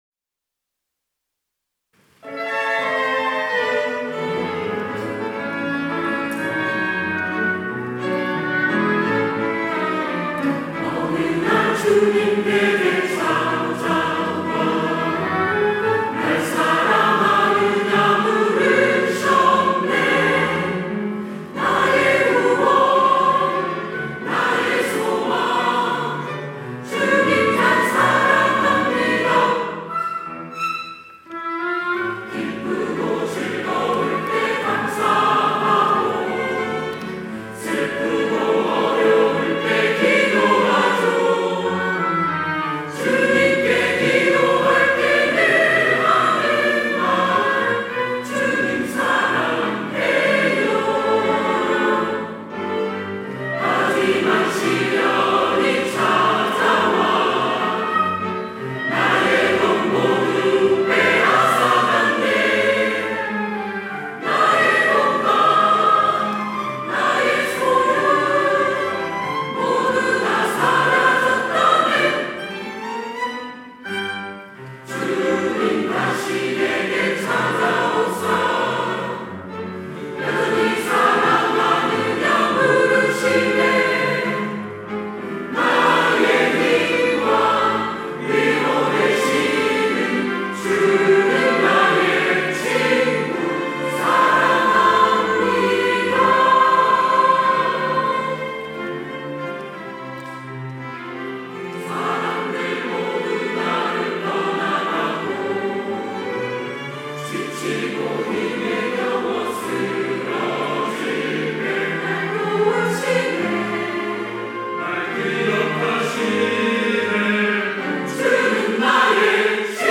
호산나(주일3부) - 주님 사랑해요
찬양대